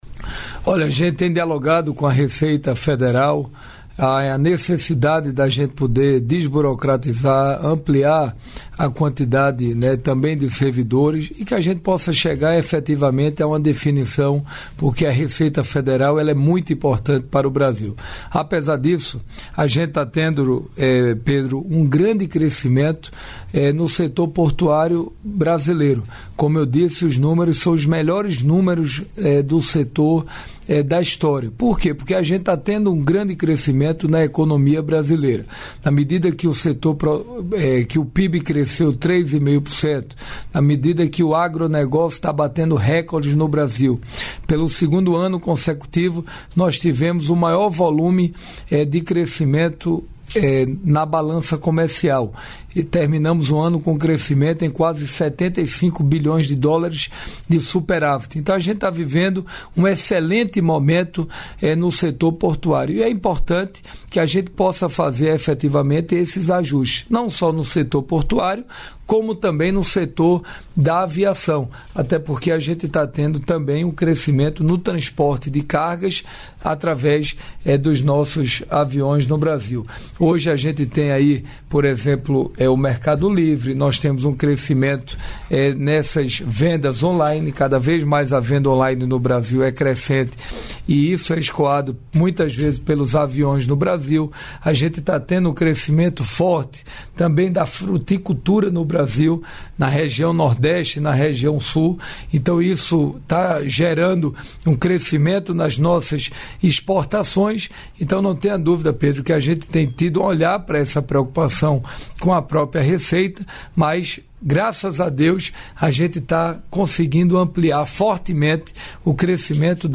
Trecho da participação do ministro de Portos e Aeroportos, Silvio Costa Filho, no programa "Bom Dia, Ministro" desta quinta-feira (06), nos estúdios da EBC, em Brasília.